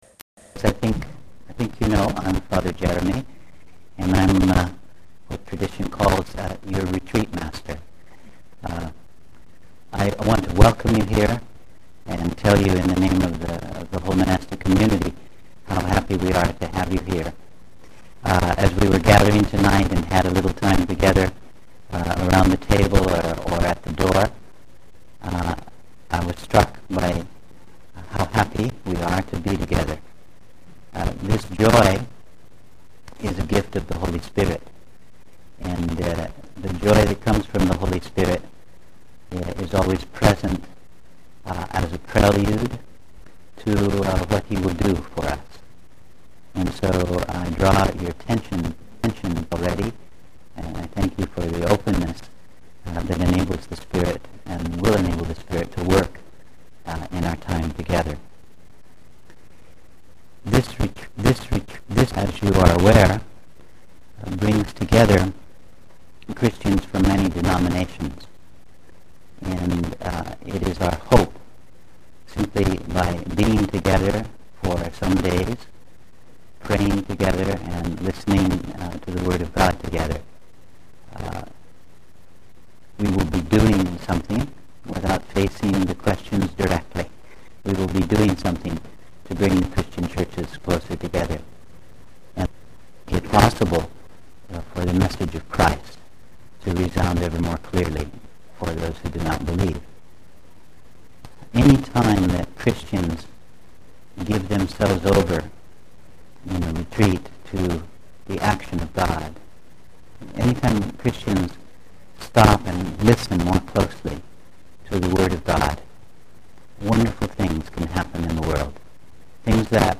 The audio quality is scratchy at first but improves as you listen (about 14 mins into the recording).